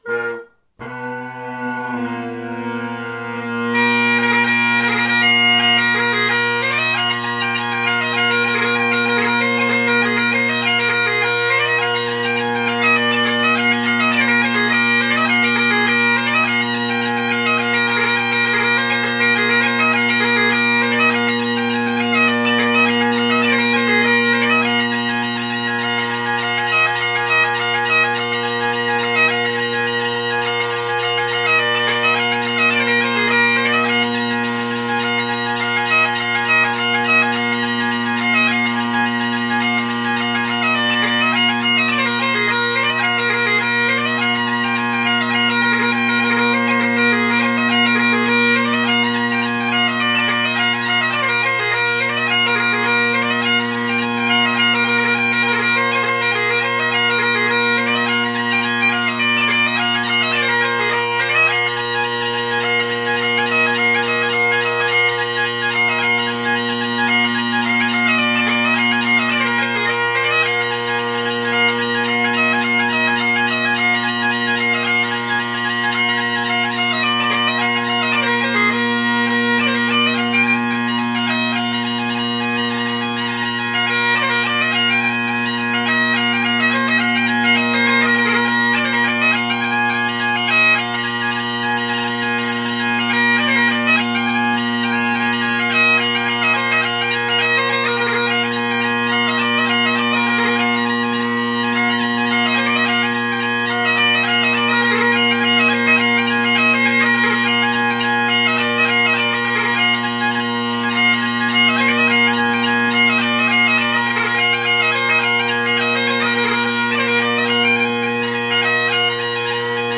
I find the tenor reeds to move with the chanter very well, the bass less so and therefore requiring more tuning, but they all settle in nice. They are very bold reeds. I get quite a nice lovely ring off the tenors, with maybe a bit of edge to them.